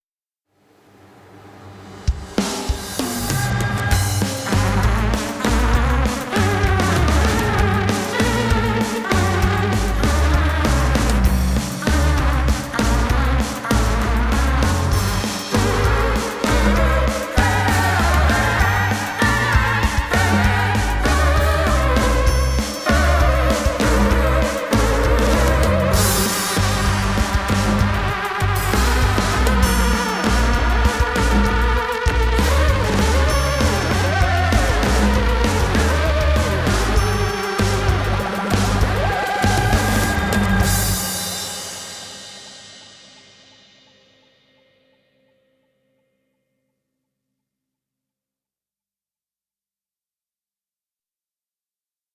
Hier drei Musikauszüge aus der aktuellen Inszenierung.